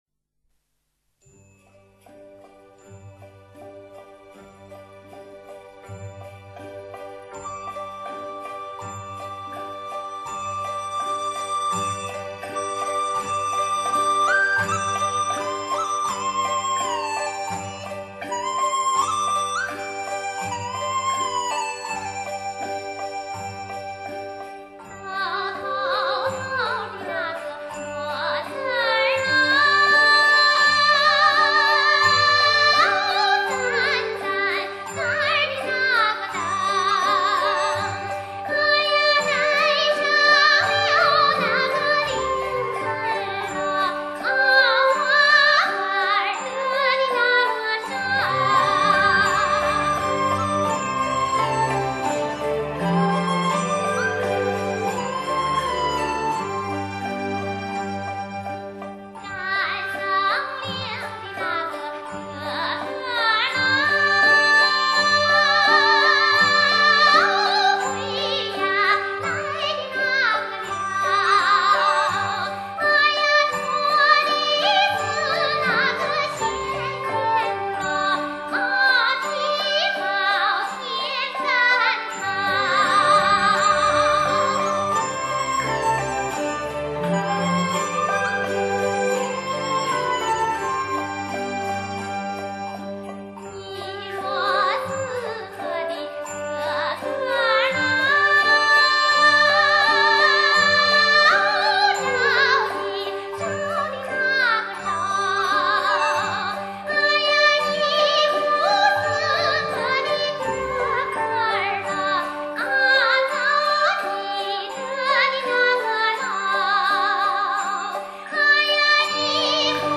陕北民歌
【歌曲简介】 陕北的信天游有数十种，《赶牲灵》的曲调就属于"信天游"的一个变种。